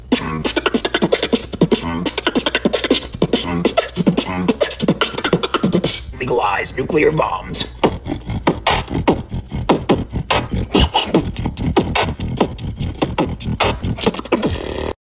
Lgb Beatbox Sound Button - Free Download & Play